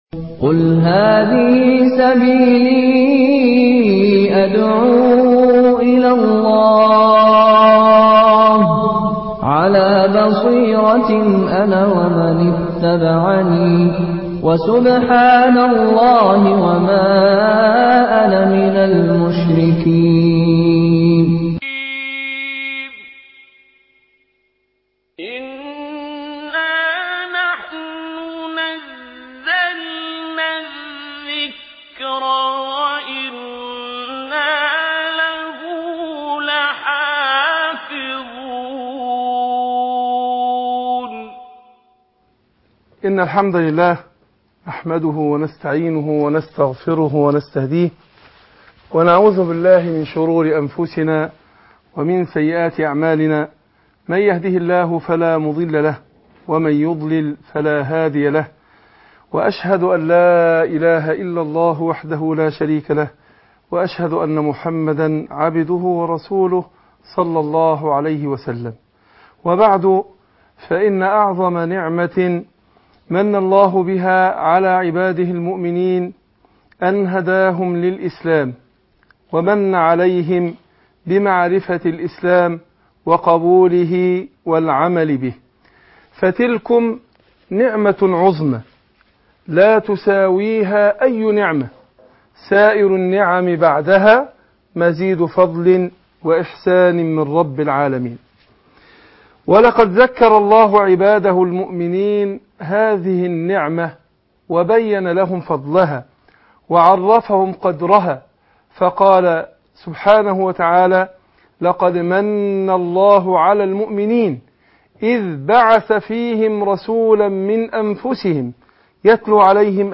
المقرأة